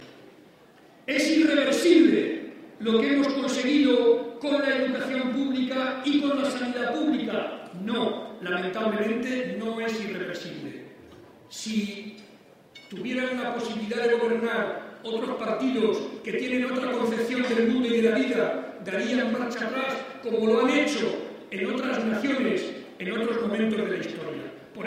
El secretario regional del PSOE y presidente de C-LM, participó en la tradicional comida de Navidad de los socialistas de Albacete.
Cortes de audio de la rueda de prensa